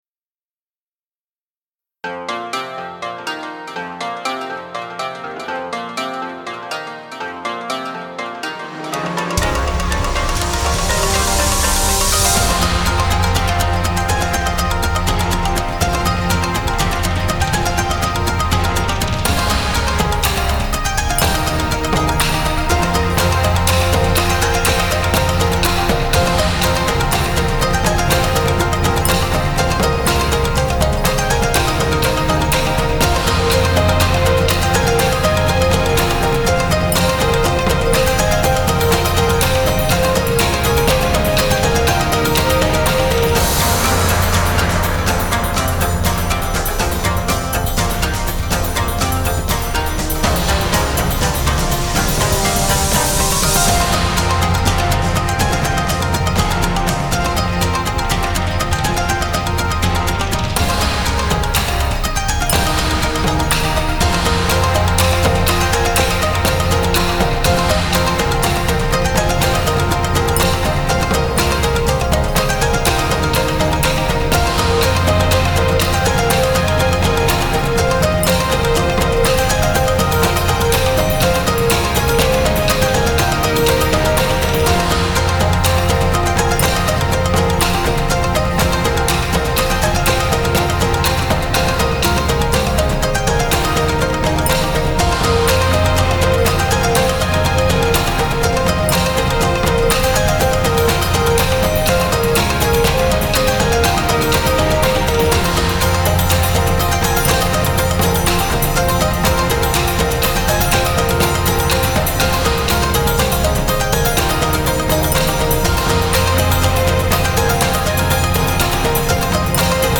和楽器で構成された旋律と和太鼓の力強さを感じ取れたなら幸いです。